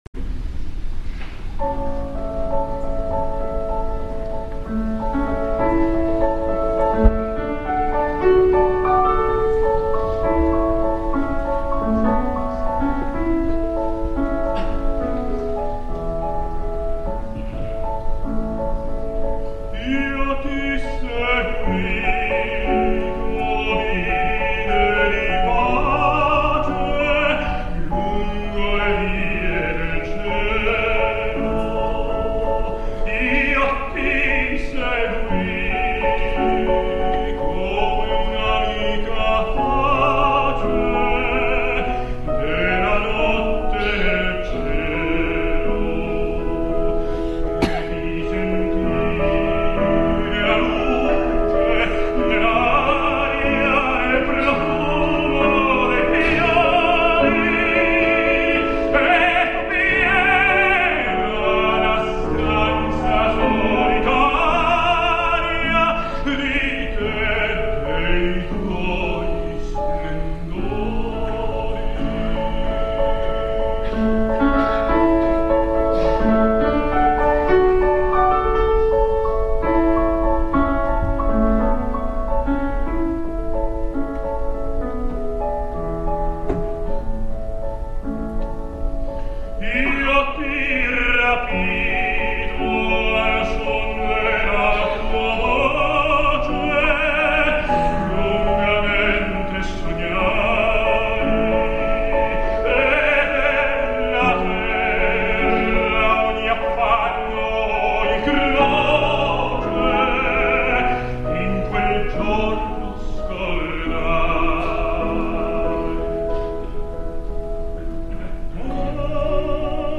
El passat 13 de gener Rolando Villazón va reaparèixer al Liceu en un recital que va alçar passions i desencisos a parts iguals.
Escoltem les cançons una mica més distesos i ens adonarem que malgrat totes les inseguretats i algun dubte vocal, els valors més villazonians romanen integres.